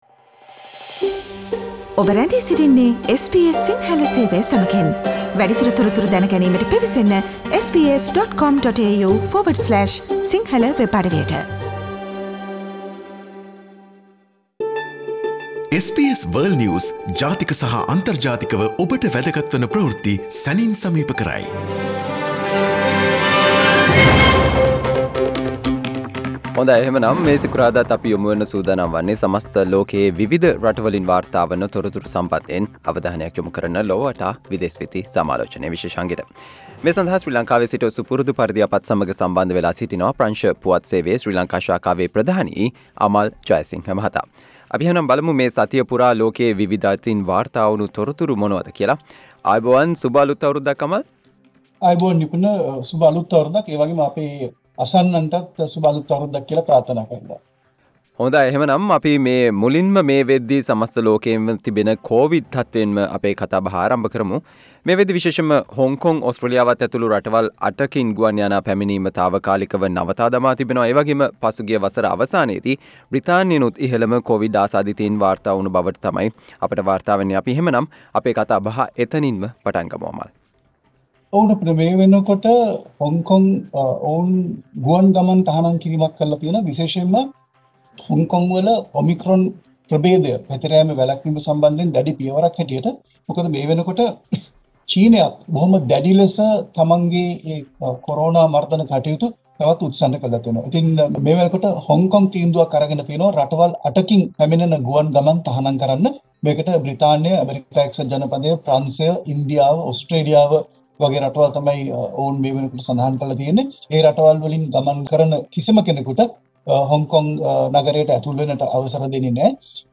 World's prominent news highlights in a few minutes- listen to SBS Sinhala Radio's weekly world News wrap on Friday